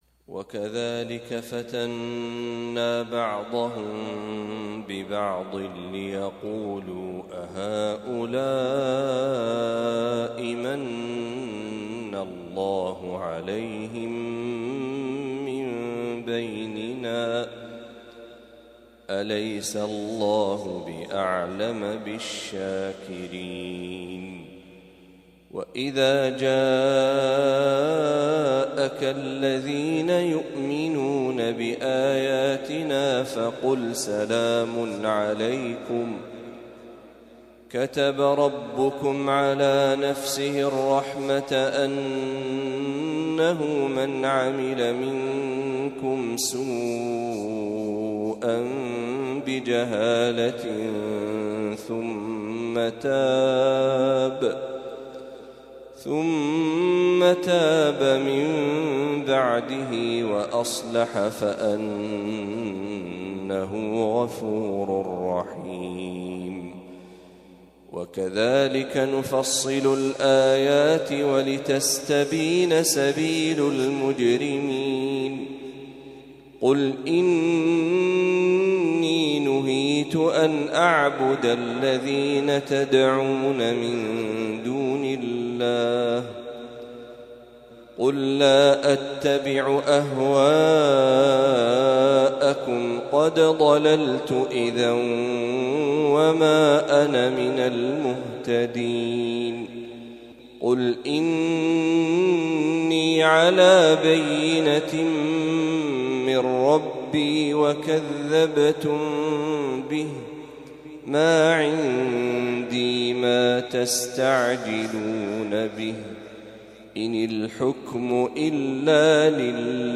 ما تيسر من سورة الأنعام | فجر السبت ١٨ ربيع الأول ١٤٤٦هـ > 1446هـ > تلاوات الشيخ محمد برهجي > المزيد - تلاوات الحرمين